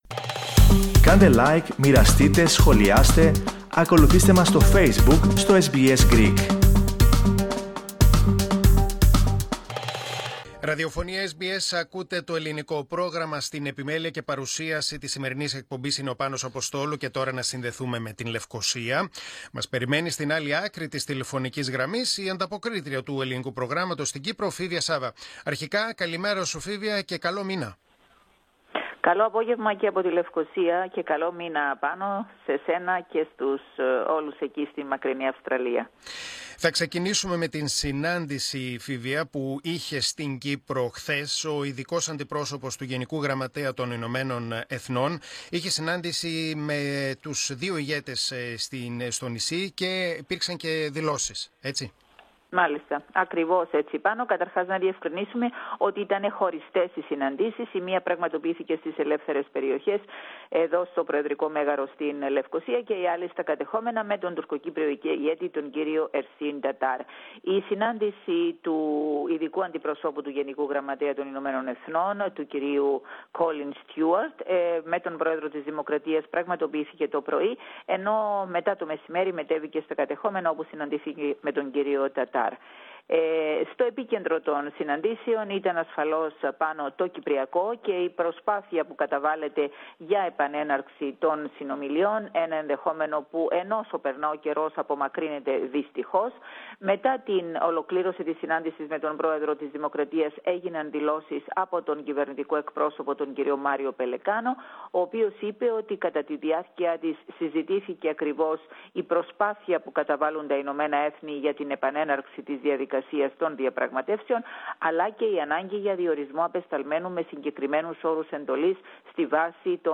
antapokrisi_kyprou_1.mp3